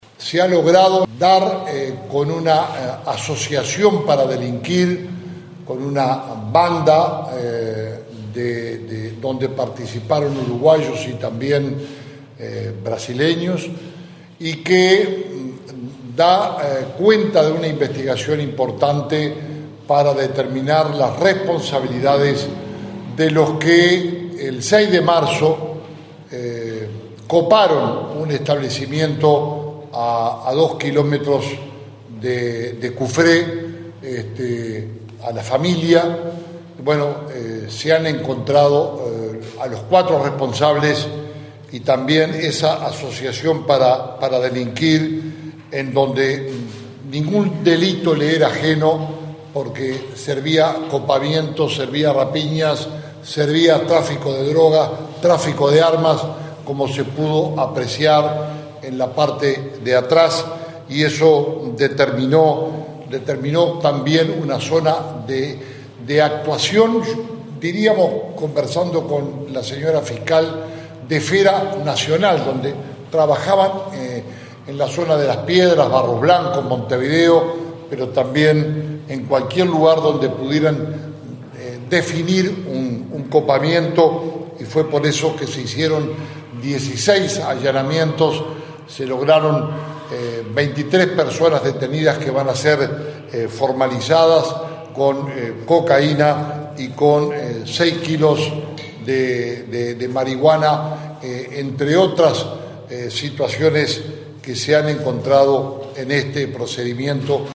Tras realizar 16 allanamientos, 23 personas resultaron detenidas, de las cuales ocho ya fueron formalizadas o condenadas, informó el ministro del Interior, Jorge Larrañaga, en conferencia de prensa en el departamento de Colonia.